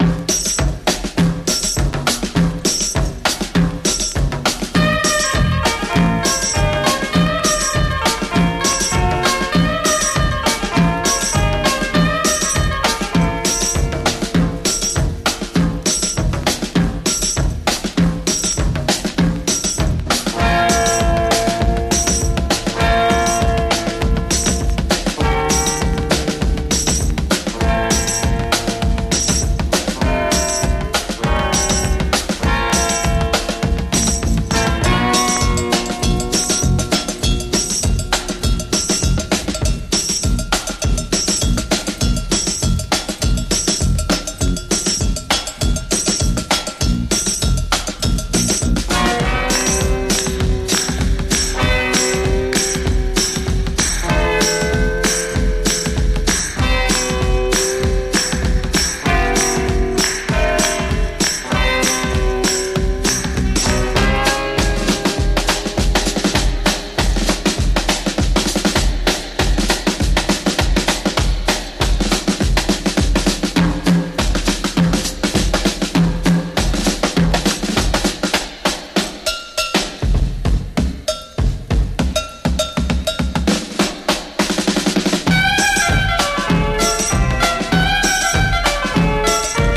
ファンキー・ドラム・インストロ・クラシック！